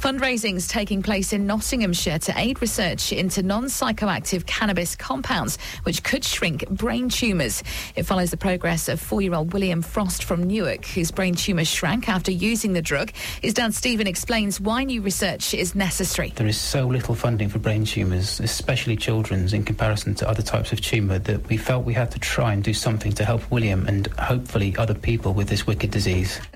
Radio Recording – Gem106
Gem106 kindly included a mention of our campaign in their hourly news bulletins this morning – here’s a copy of the recording…